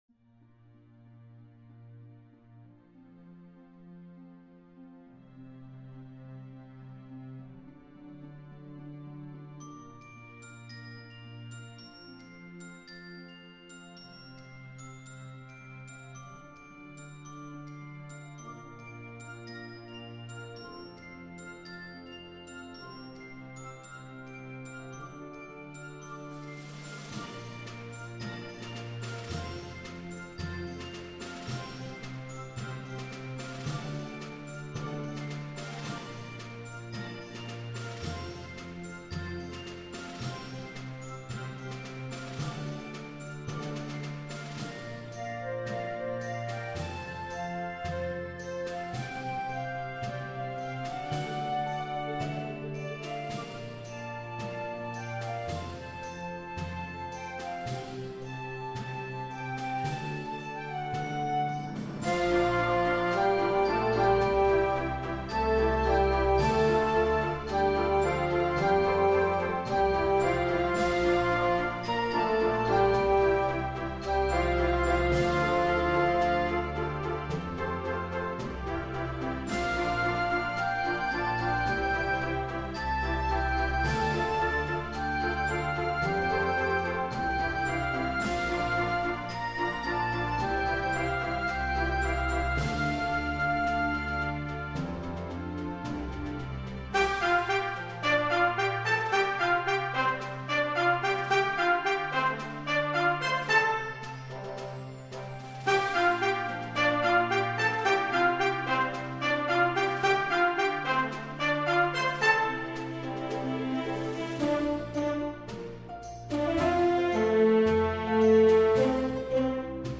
I made this remix for the contest!
genre:remix